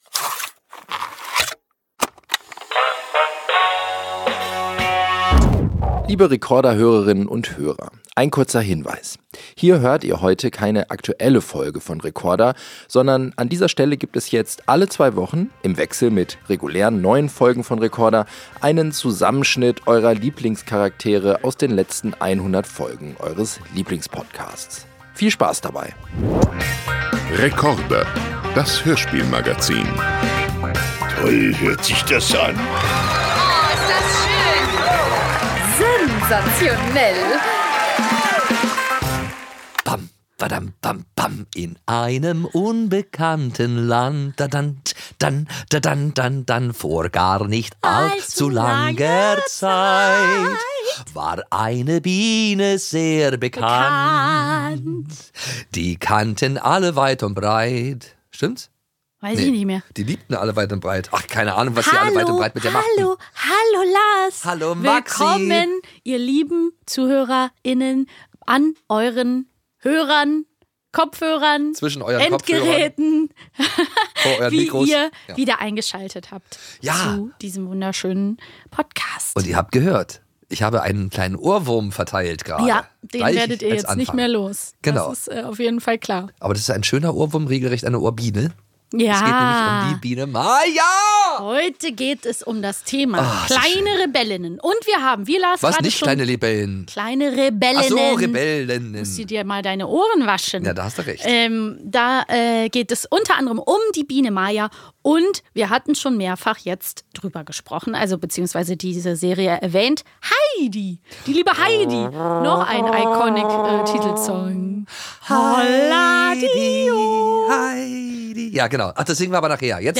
Beschreibung vor 6 Monaten In dieser Sonderausgabe von Rekorder hört ihr einen Zusammenschnitt aus Folgen über die Biene Maja und Nils Holgersson – mit einem Abstecher ins sonnige Alpenland zu Heidi.